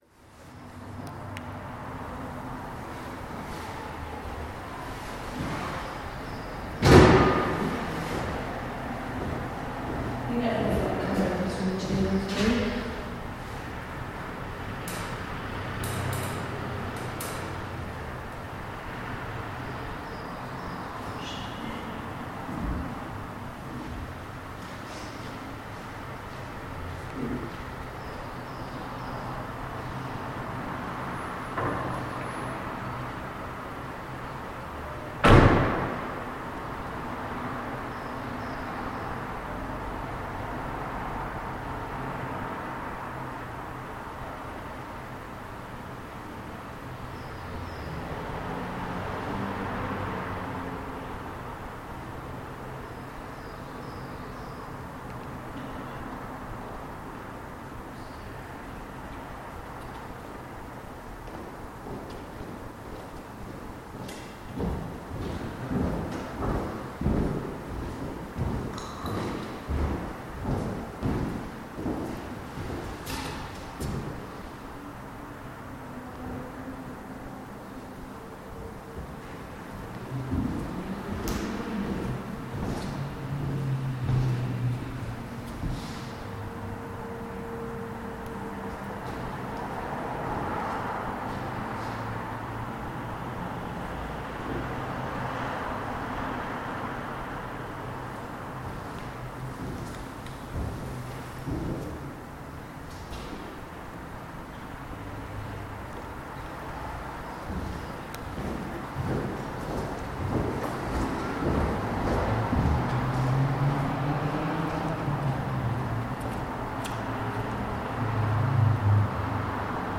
Inside Walcot Chapel